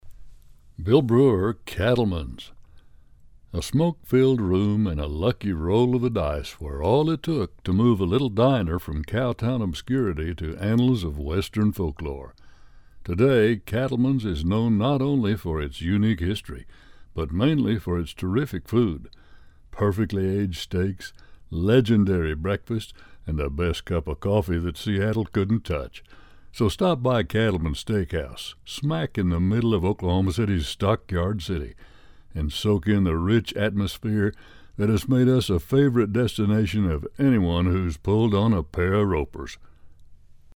Sprechprobe: Sonstiges (Muttersprache):
A Bass/Baritone with a hint of the Southwest